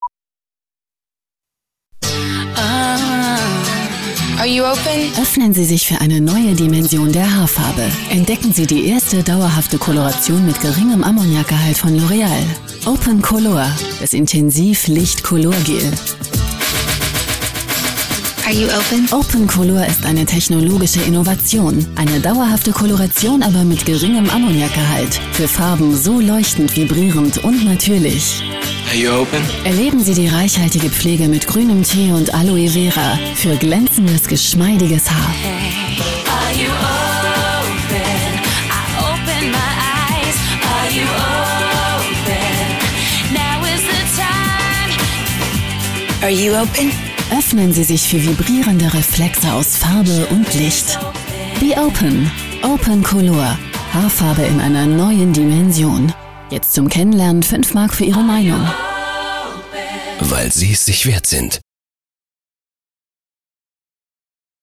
Sprechprobe: Werbung (Muttersprache):
female voice over talent german for commercials, tv, radio, synchron, dubbing, audio-books, documentaries, e-learning, podcast